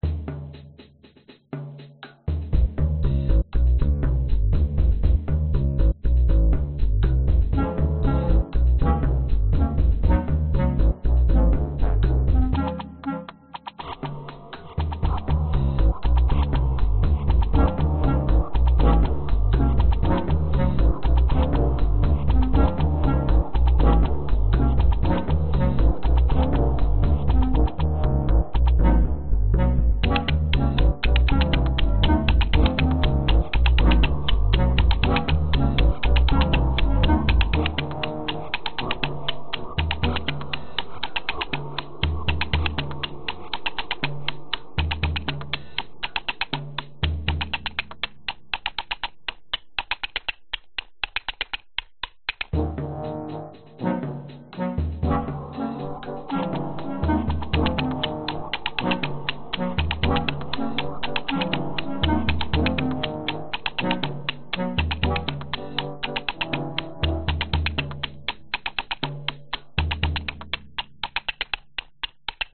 Tag: 原声 贝斯 器乐 循环